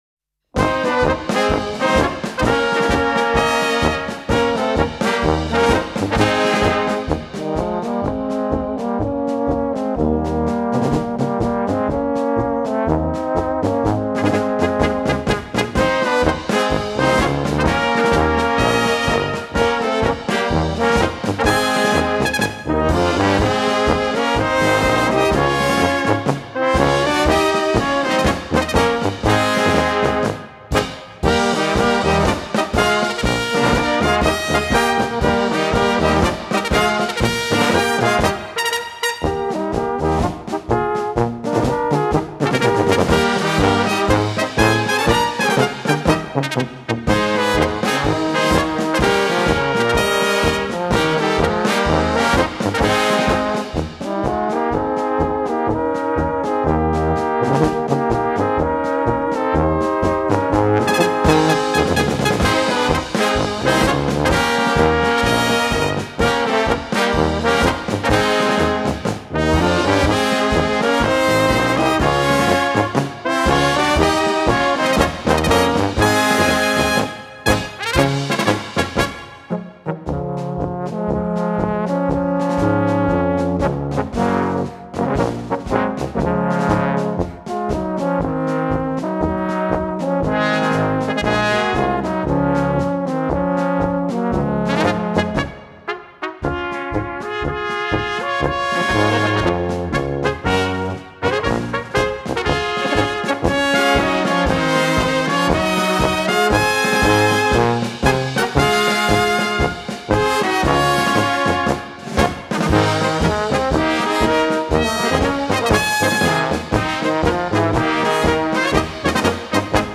Gattung: Polka für kleine Besetzung
Besetzung: Kleine Blasmusik-Besetzung
Trompete Bb
Flügelhorn Bb 1
Tenorhorn Bb 1
Tuba C
Schlagzeug